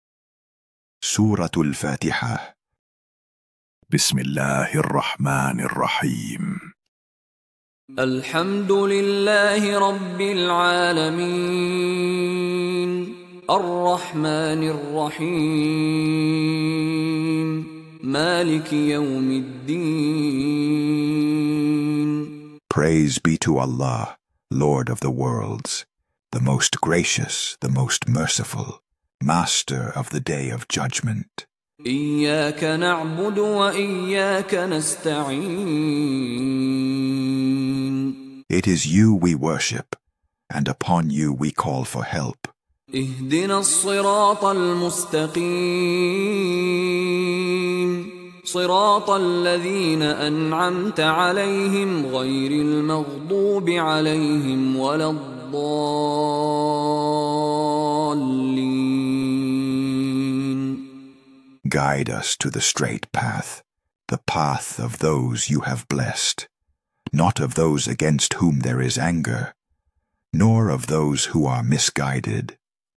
Created with calm pacing, respectful audio design, and accessible translations (English and more to come), this project is to support anyone seeking a closer, more contemplative relationship with the Qur’an in their daily life.